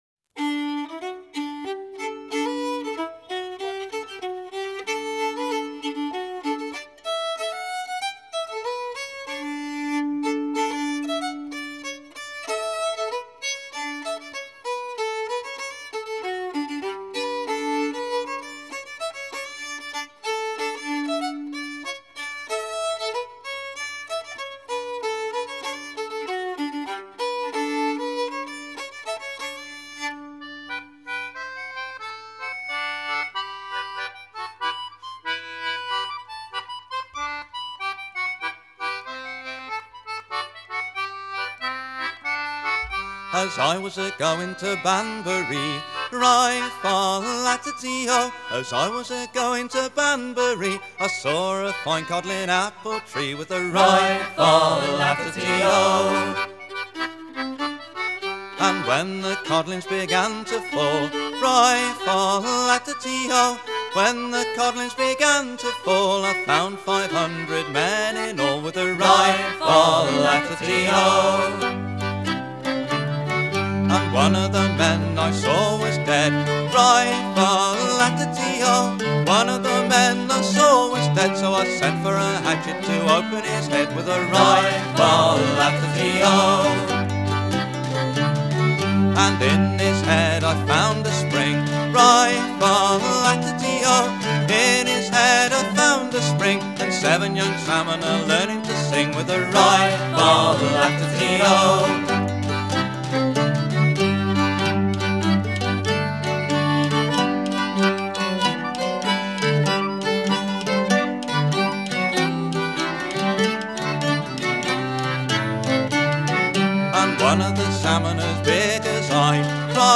— albeit sillier — – English folk song…